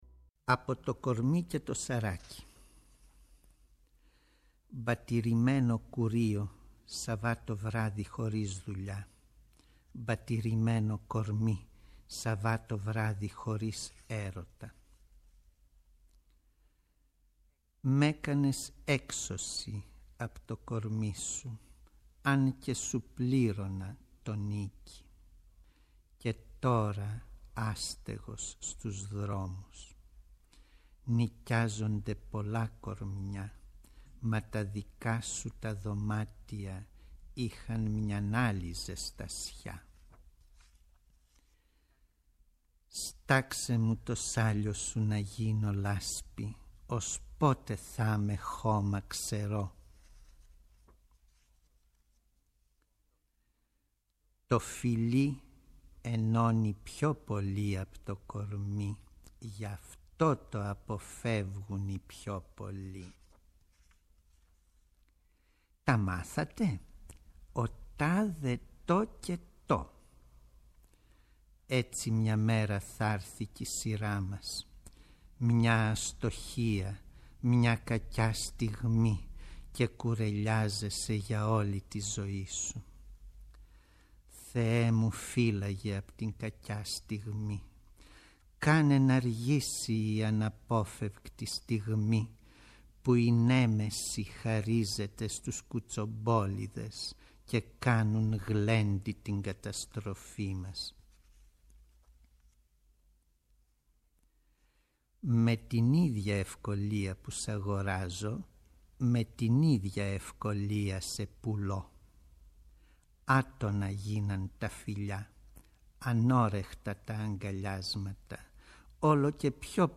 Παράλληλα, ακούγονται απαγγελίες με τη φωνή του ποιητή και τραγούδια που γράφτηκαν πάνω σε στίχους του.